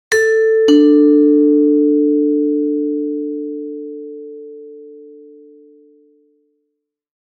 Short Doorbell Sound Effect Free Download
Short Doorbell